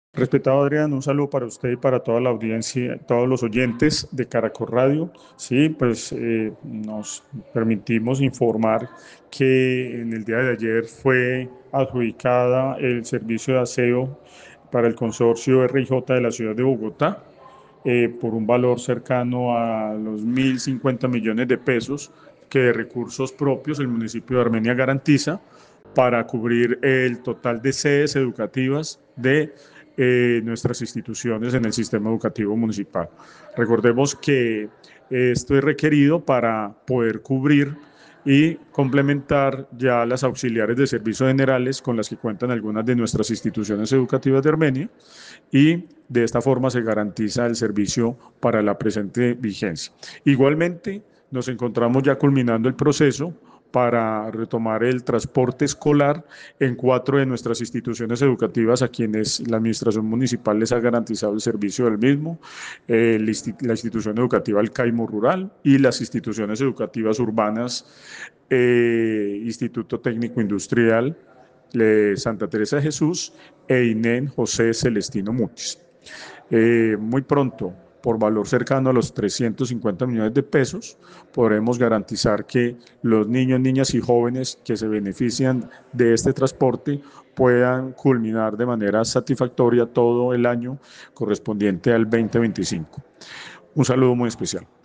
Antonio Vélez, secretario de educación de Armenia